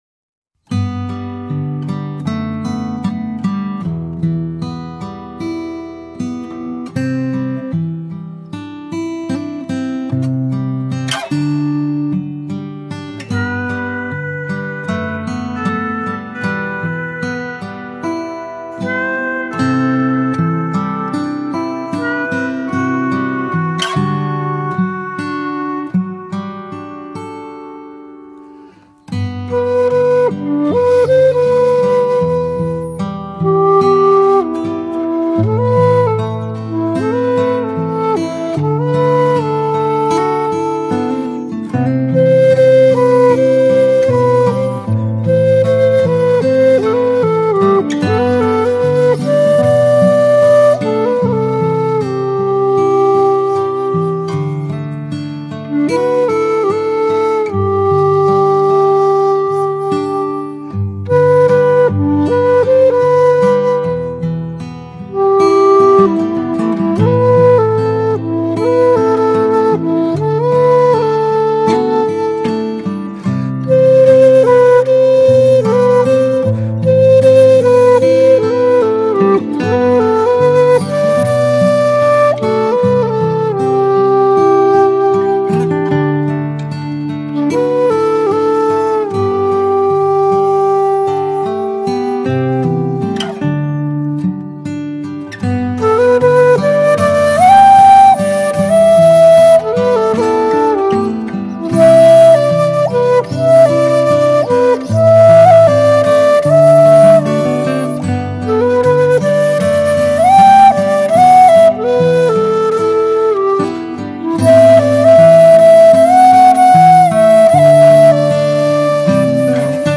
笛箫
古琴
琵琶
古筝
小提琴
人声和音